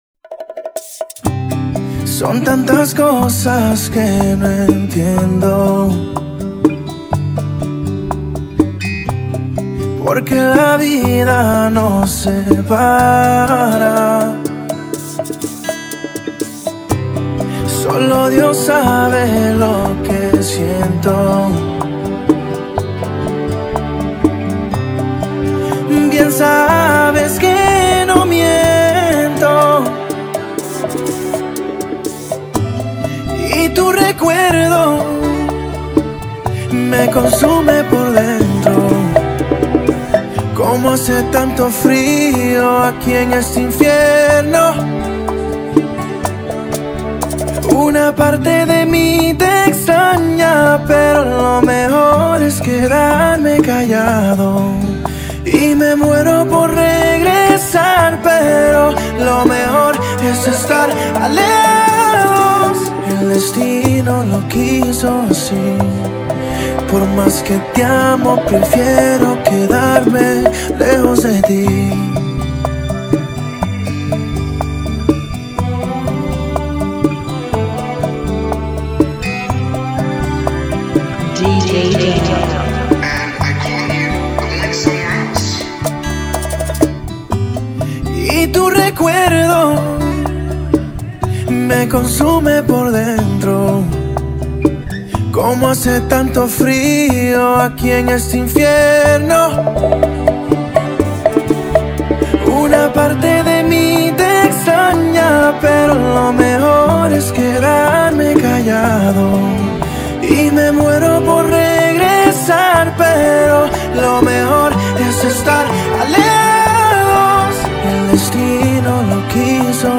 (122 BPM)
Genre: Bachata Remix